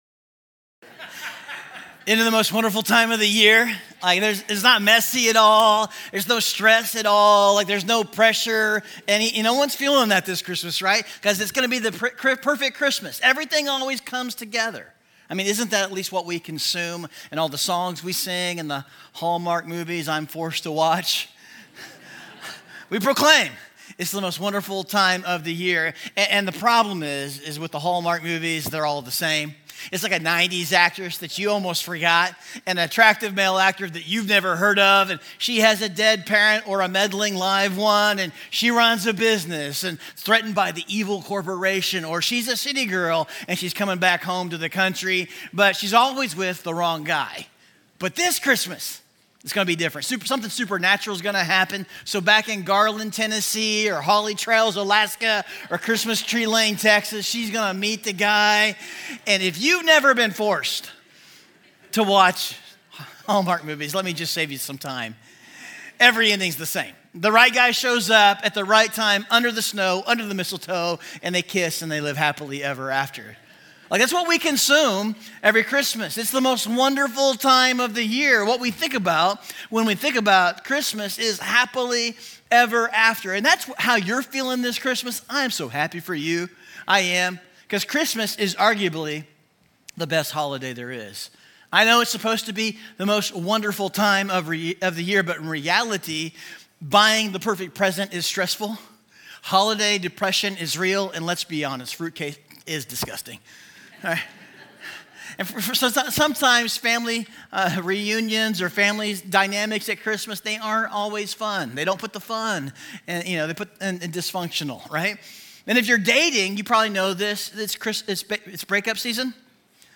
Who_Needs_Christmas_Week_02_Message_Audio.mp3